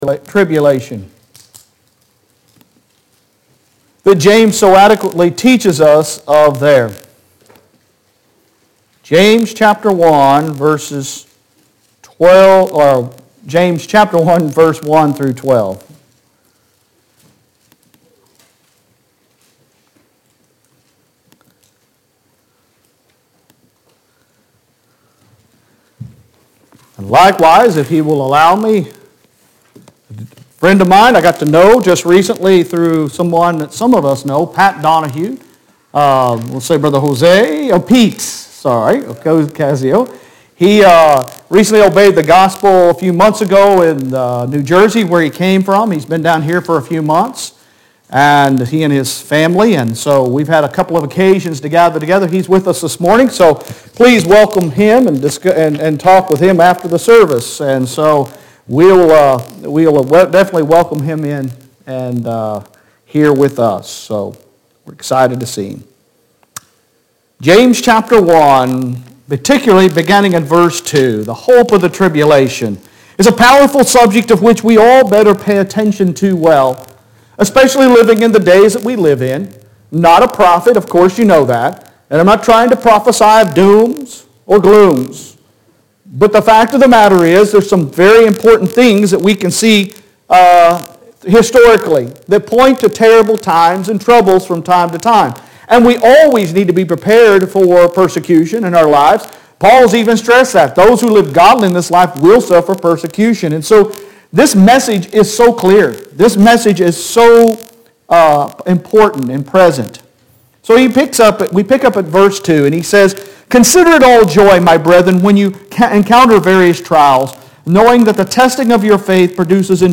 Sun AM Sermon 02.06.22